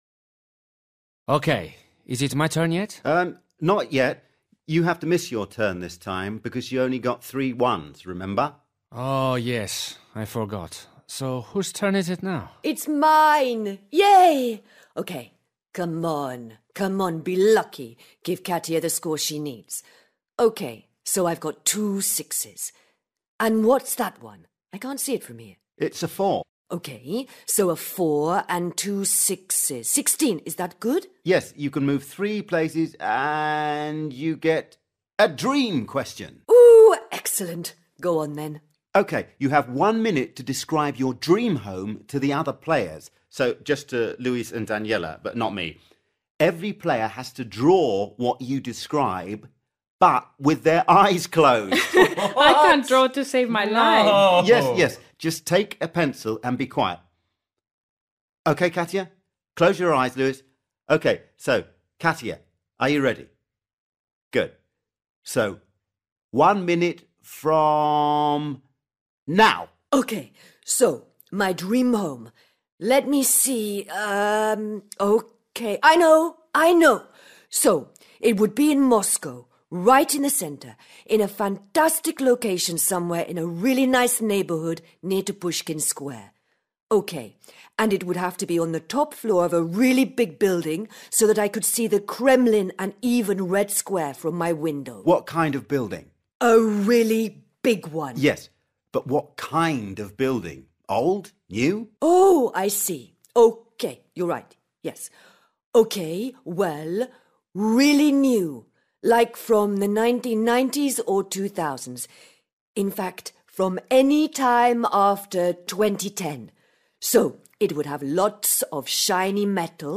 A   Listen to a group of friends talking.